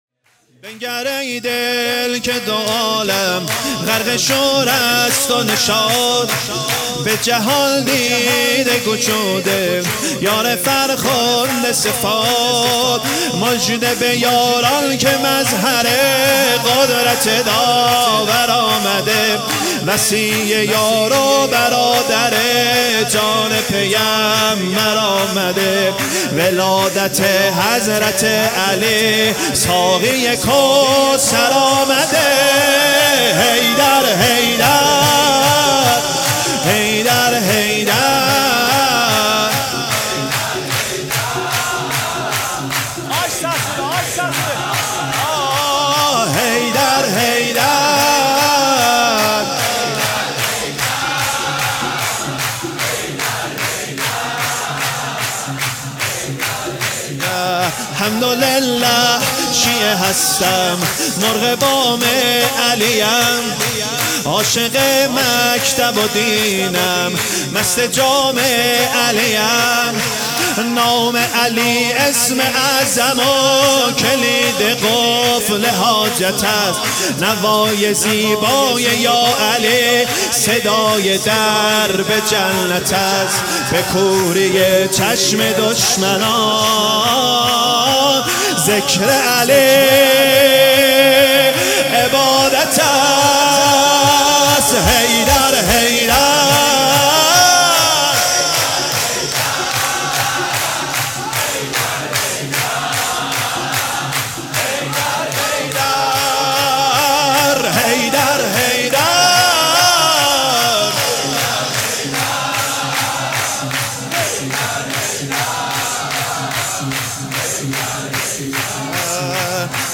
سرود | بنگر ای دل
ولادت امام علی(ع)